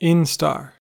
An instar (/ˈɪnstɑːr/
En-us-instar.ogg.mp3